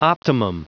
Prononciation du mot optimum en anglais (fichier audio)
Prononciation du mot : optimum